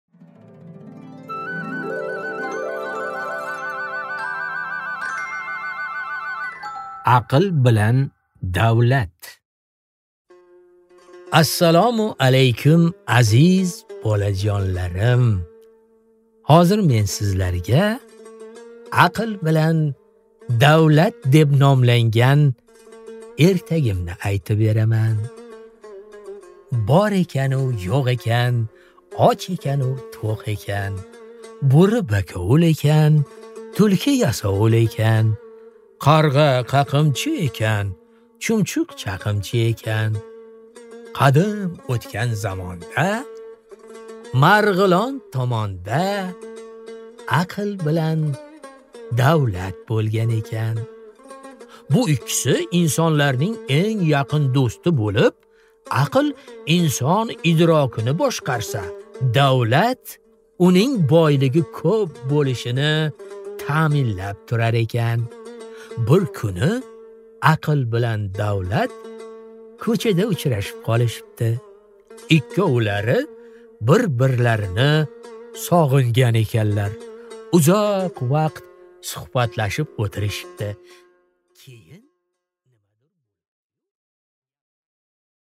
Аудиокнига Aql bilan Davlat | Библиотека аудиокниг
Прослушать и бесплатно скачать фрагмент аудиокниги